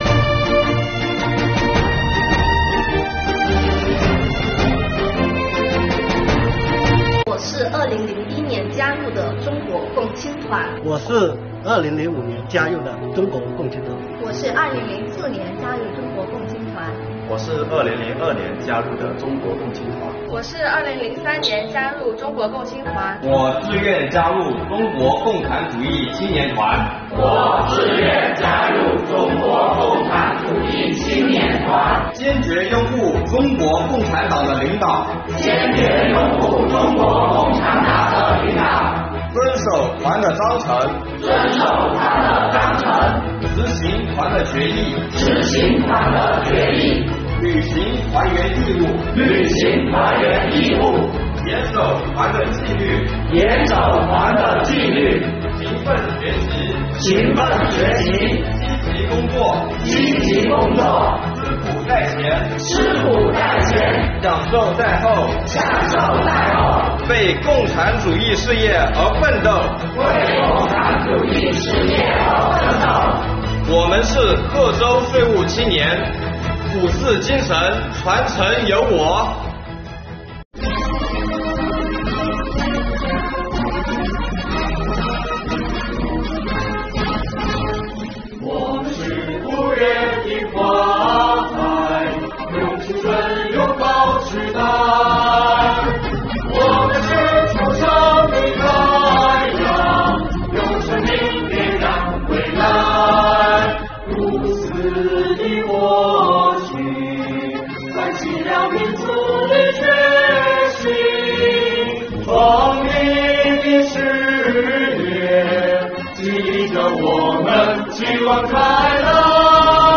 贺州税务团员青年们用歌声传唱五四风采
贺州税务团员青年们用歌声传唱五四风采，用激情、智慧和力量唱响新时代的青春之歌，绽放耀眼的青春光芒。